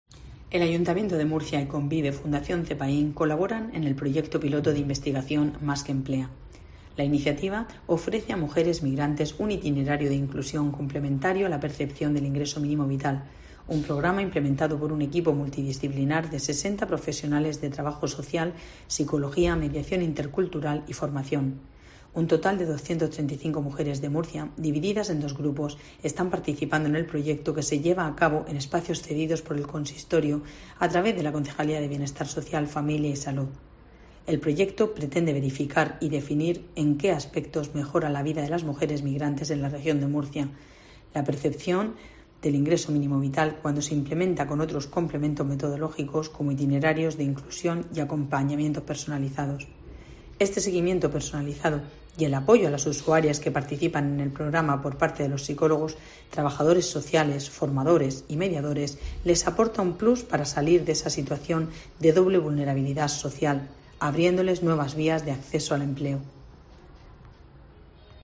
Pilar Torres, concejala de Bienestar Social, Familia y Salud